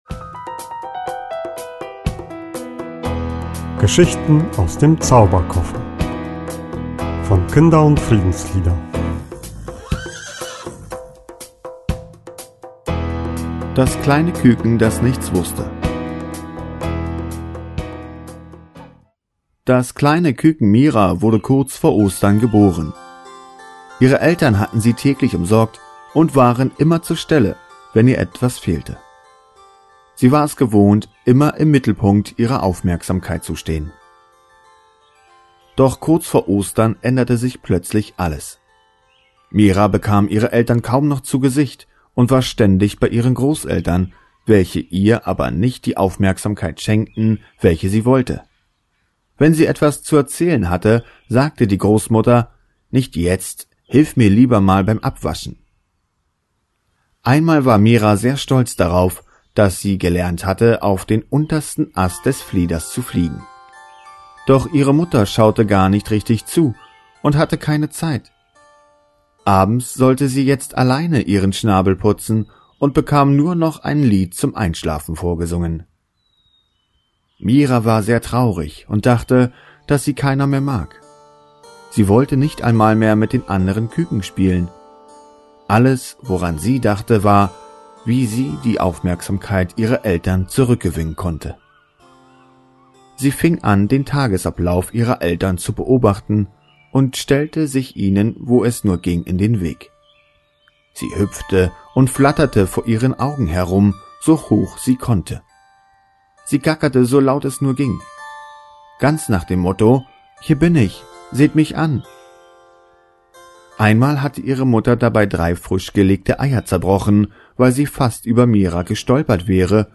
Kindergeschichte, Ostergeschichte, Geschichte für Kinder zu Ostern, Gute-Nacht-Geschichte, Geschichte zu Ostern für den Kindergarten
Hörbuch (MP3)